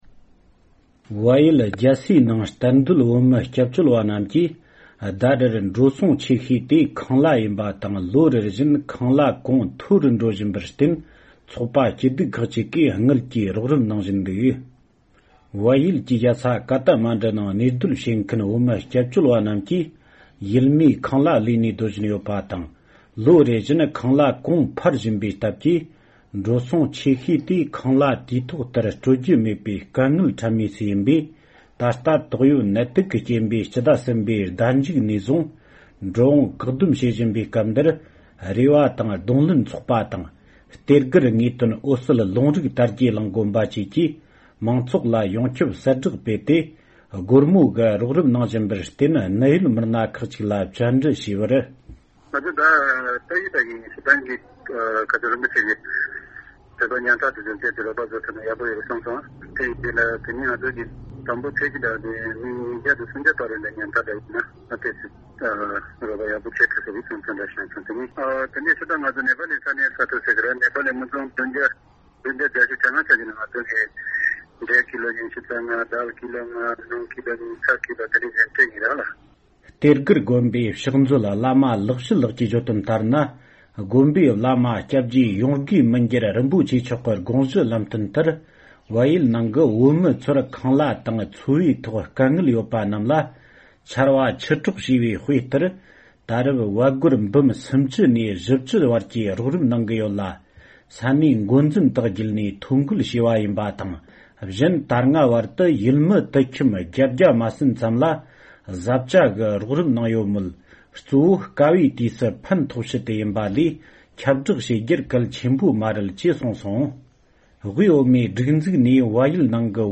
བཅར་འདྲི་ཞུས་པ་ཡིན།